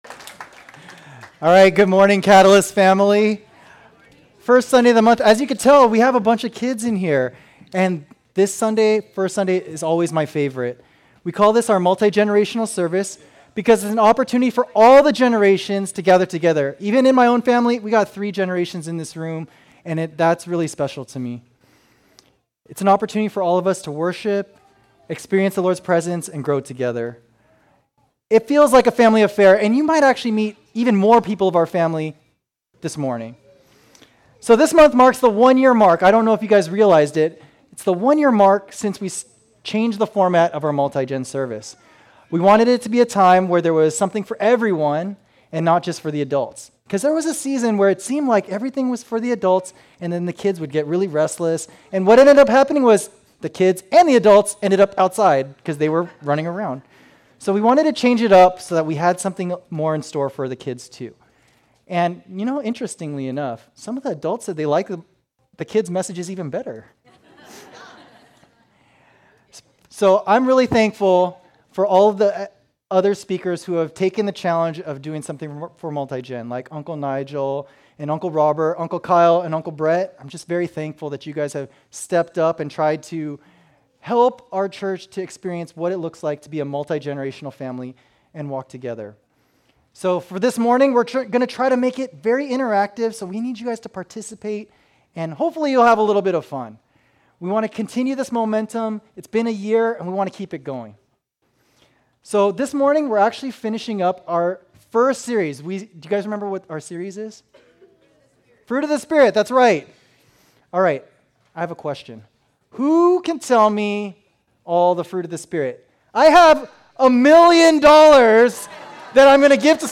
Sermons | Catalyst Christian Community